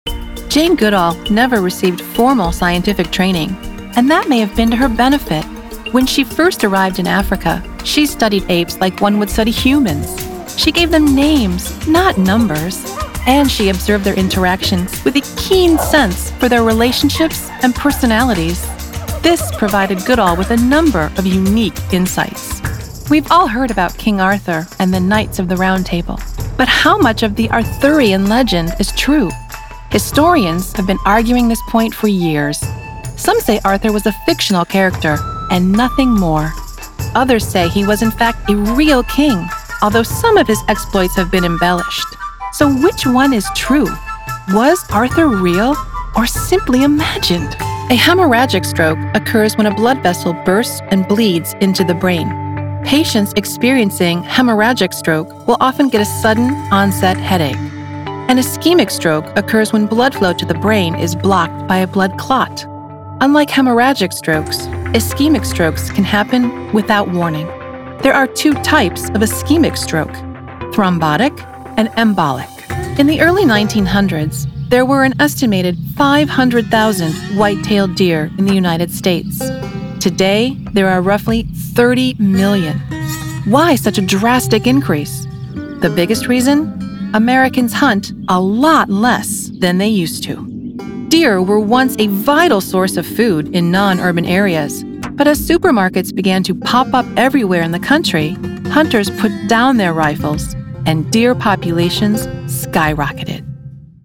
Highly motivated and a self-starter with a persuasive and trustworthy sound.
For narration
English - Midwestern U.S. English
Middle Aged
I have a warm, friendly sound and enjoy nature and travel.